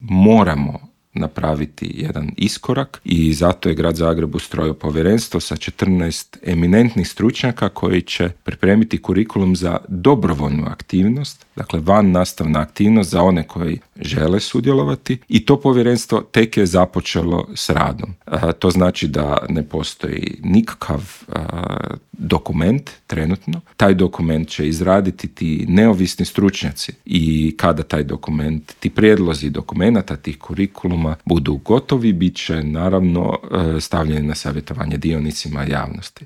Više od 50 tisuća djece u Hrvatskoj suočava se s mentalnim teškoćama, broj djece s teškoćama u zagrebačkim osnovnim školama više se nego udvostručio u zadnjih deset godina, a više od trećine, odnosno čak 36 posto djece u Hrvatskoj je pretilo, dok je to na razini Europe slučaj s njih 25 posto, iznio je alarmantne podatke Hrvatskog zavoda za javno zdravstvo i UNICEF-a u Intervjuu Media servisa pročelnik Gradskog ureda za obrazovanje Luka Juroš i poručio: